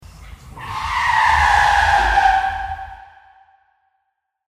Звуки тормозов автомобиля
На этой странице собраны реалистичные звуки тормозов автомобилей: от резкого визга до плавного скрипа.